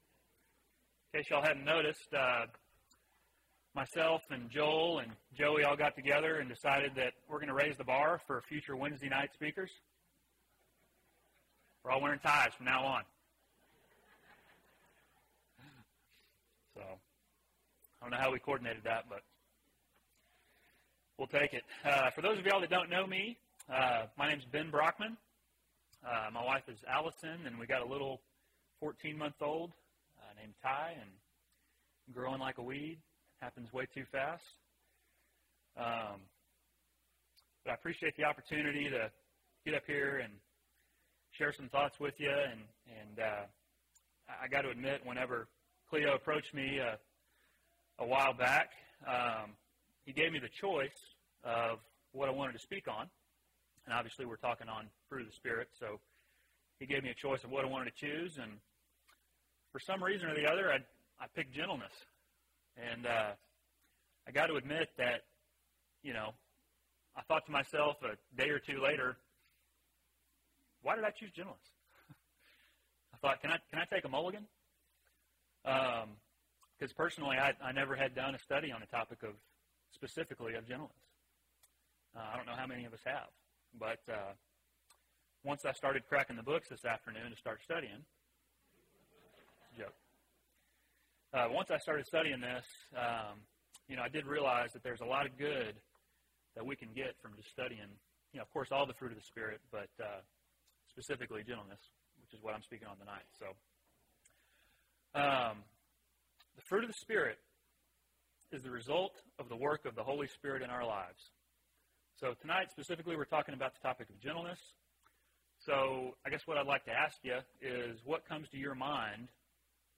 Gentleness (7 of 12) – Bible Lesson Recording
Wednesday PM Bible Class